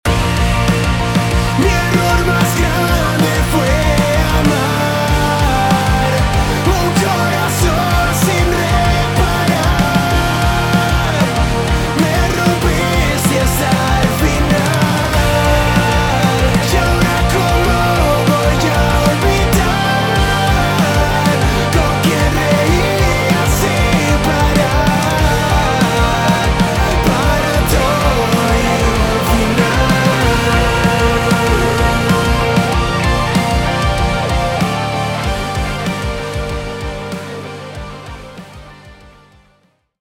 Género: Alternative / Pop Rock.